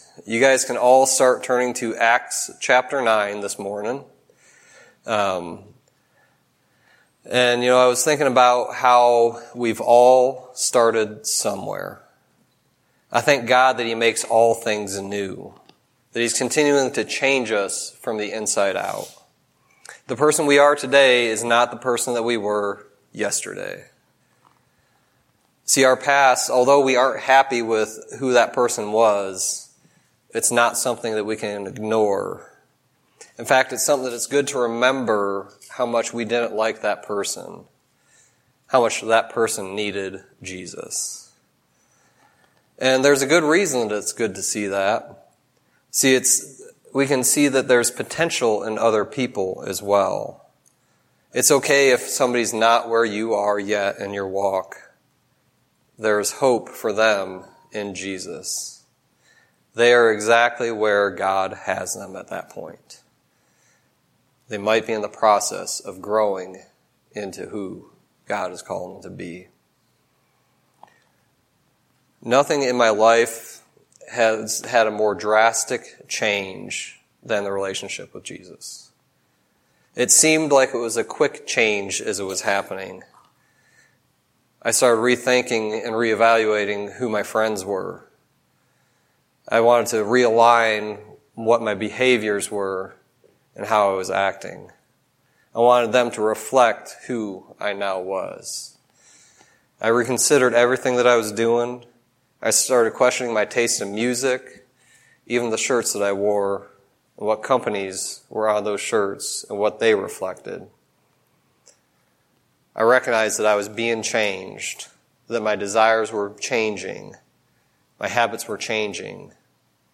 Sermon messages available online.
Acts 9:1-20 Service Type: Sunday Teaching God is looking for our response to His instructions.